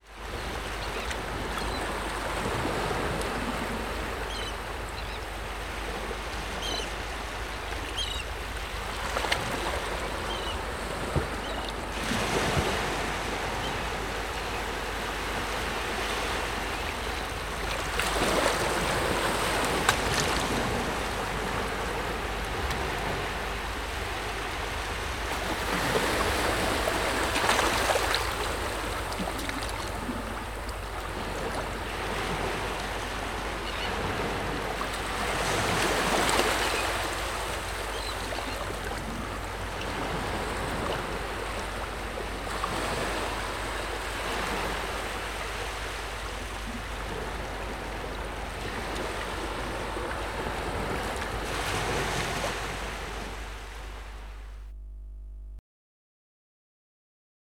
AMBSea-Mer-vagues-moyennes-et-mouettes-ID-0267-LS-1-.mp3